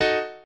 piano5_10.ogg